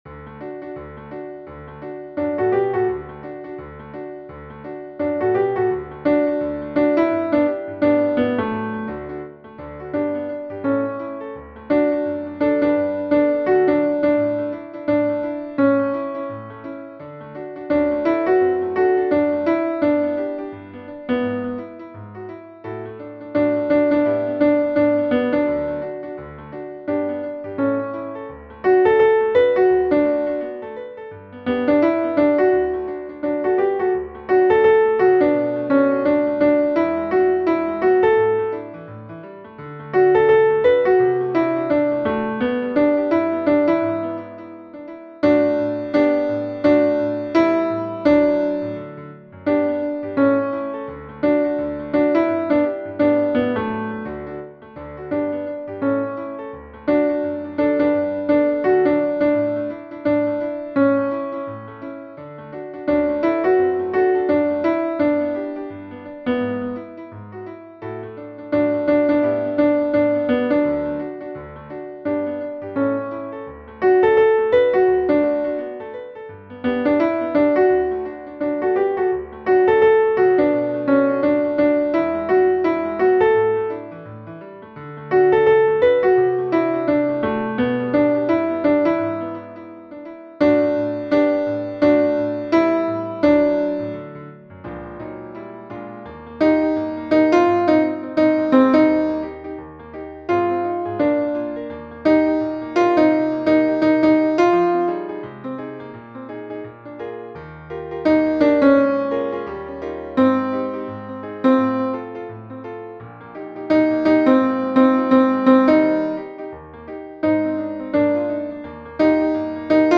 Lass_die_Sonne_in_dein_Herz_Alto_100.mp3